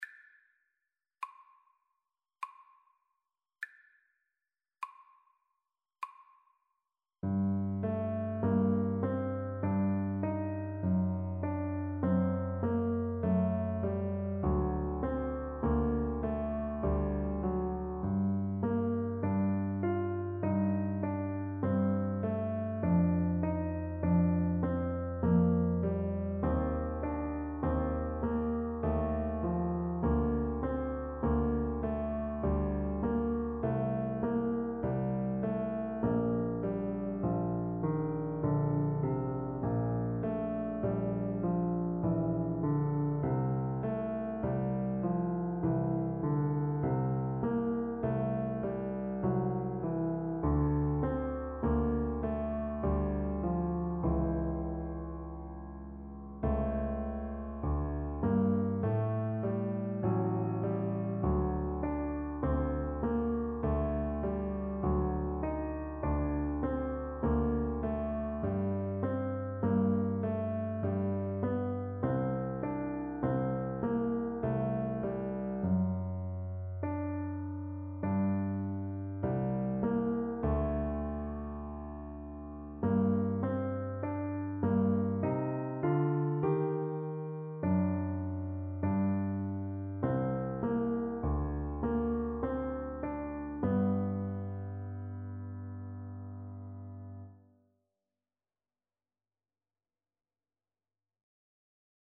3/4 (View more 3/4 Music)
Largo
Classical (View more Classical Trombone Music)